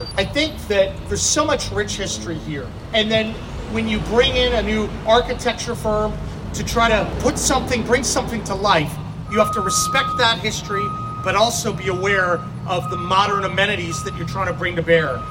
Governor Patrick Morrisey was in Keyser this past Friday to help cut the ribbon on the new Judicial Annex building for the Mineral County Courthouse.